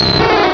sound / direct_sound_samples / cries / dratini.aif